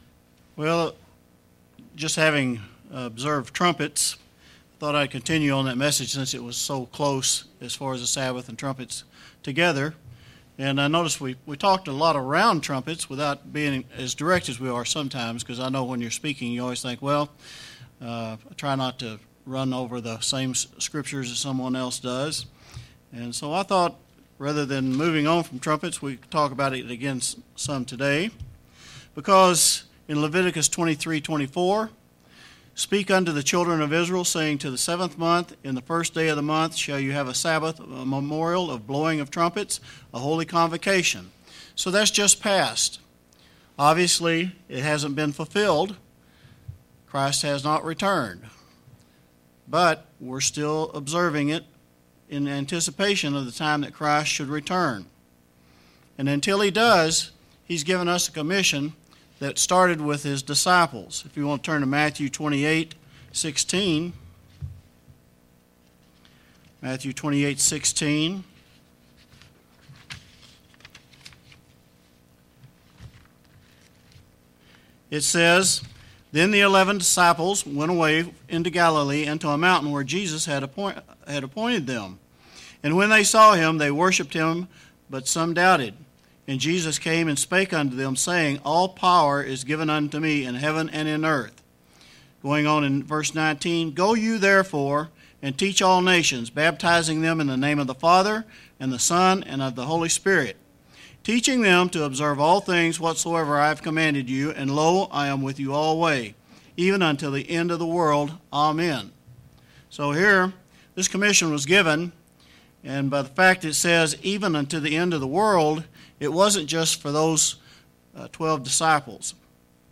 We have hope in the coming Kingdom of God's establishment on this earth. In this sermon, the speaker looks into the topic of the Feast of Trumpets and the hope that we have for the events after this prophetic time in man's future.
Given in Springfield, MO